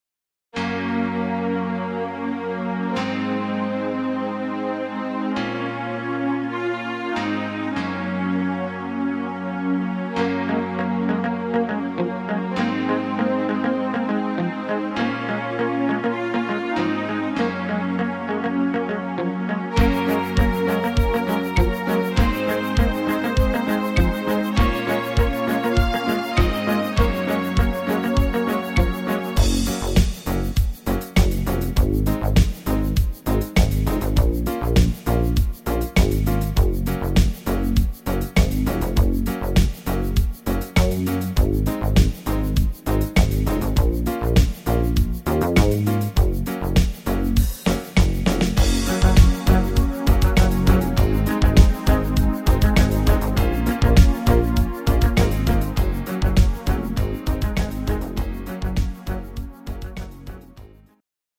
Rhythmus  Dancefloor
Art  Englisch, Pop